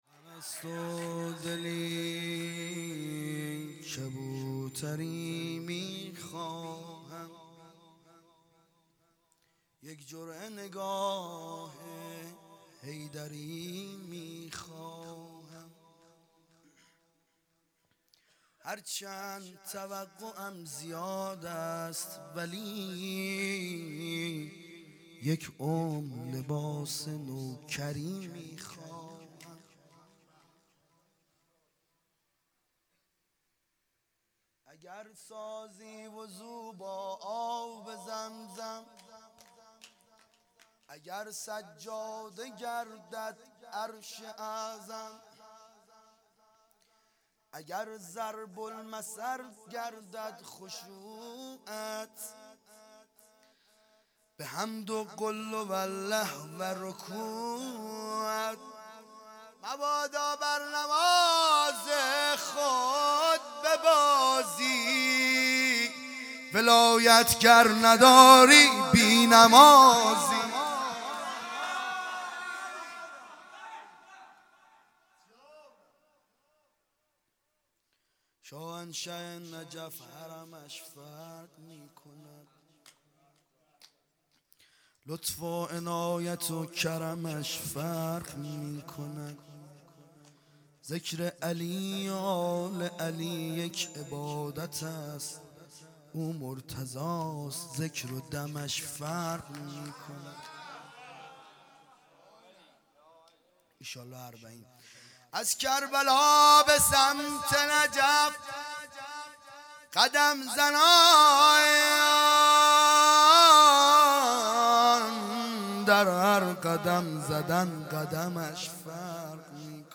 هیئت دانشجویی فاطمیون دانشگاه یزد - مناجات
شب چهارم محرم ۹۶